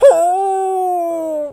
pgs/Assets/Audio/Animal_Impersonations/wolf_hurt_08.wav at master
wolf_hurt_08.wav